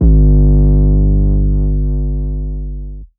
808s
Metro 808 Blow.wav